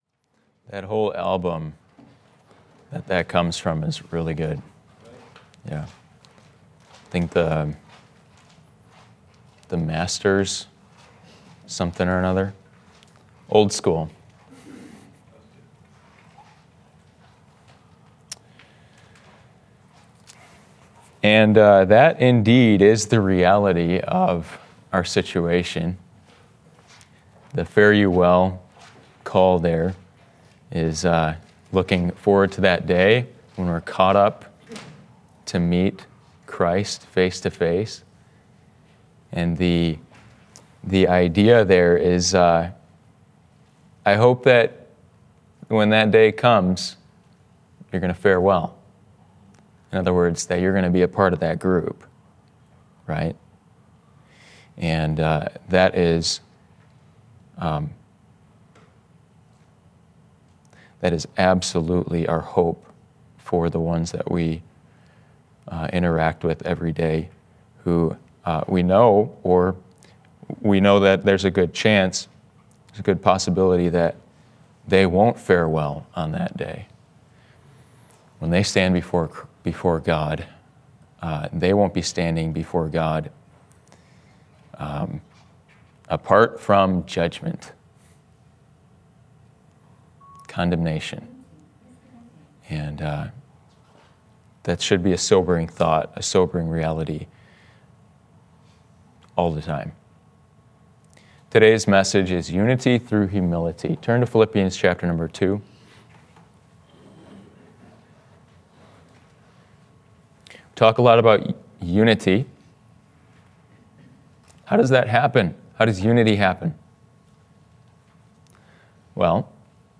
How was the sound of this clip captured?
Unity Through Humility —Sunday AM Service— Passage